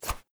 knife_swing.ogg